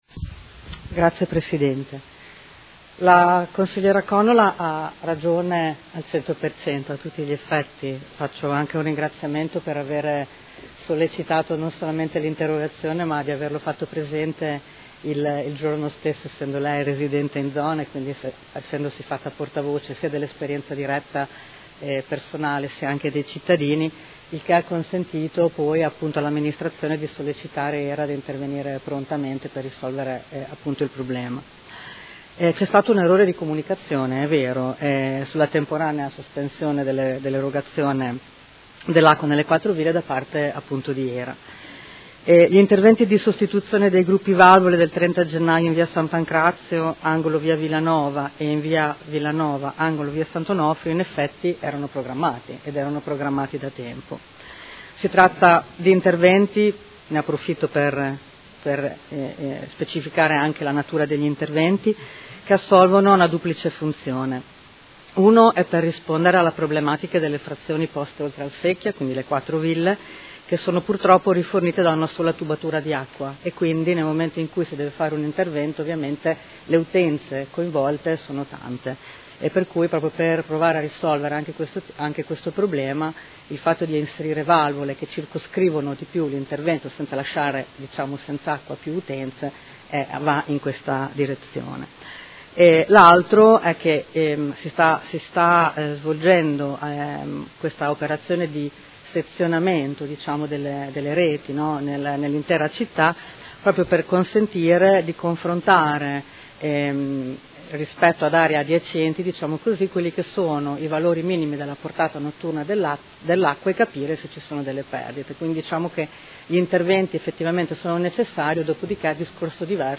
Alessandra Filippi — Sito Audio Consiglio Comunale